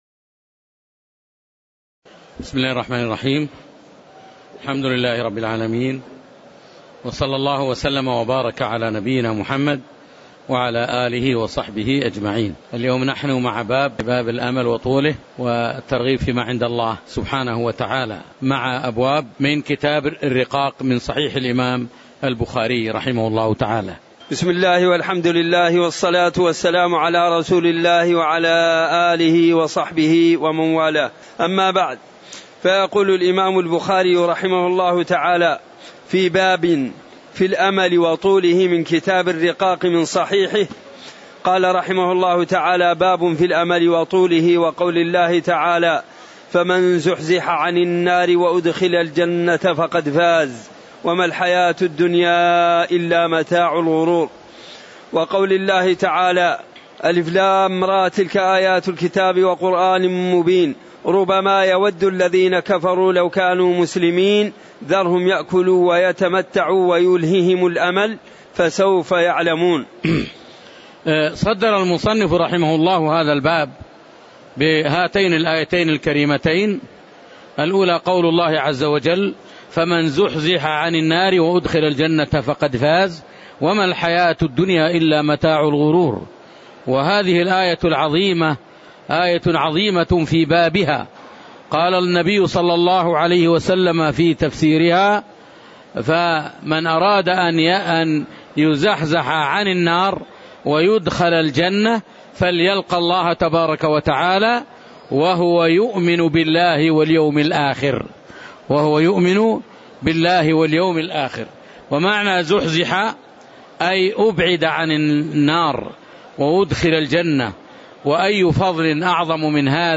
تاريخ النشر ٢ رمضان ١٤٣٩ هـ المكان: المسجد النبوي الشيخ